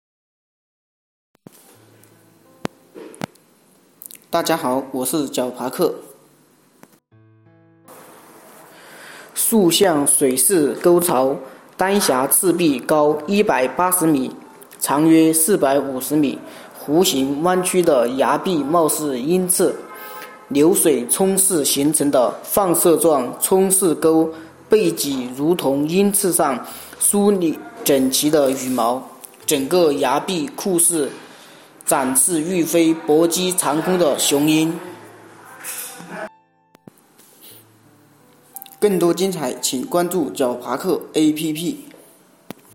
解说词